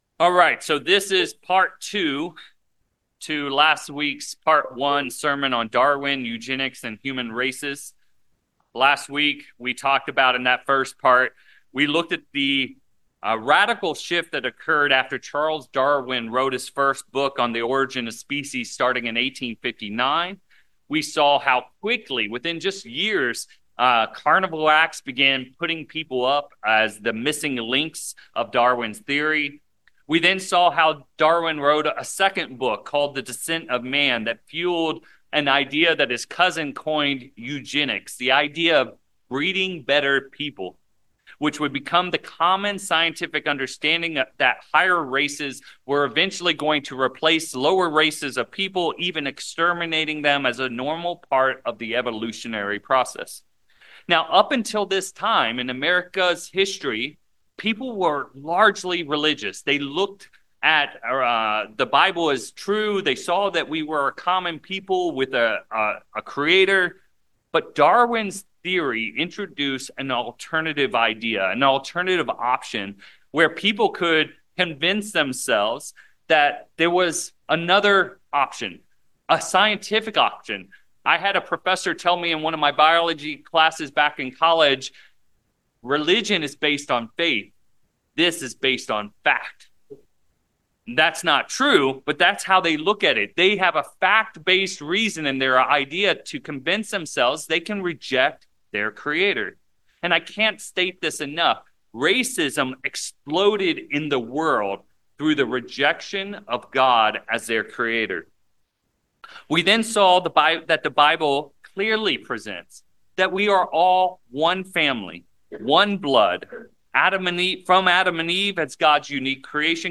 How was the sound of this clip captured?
Given in San Francisco Bay Area, CA Petaluma, CA San Jose, CA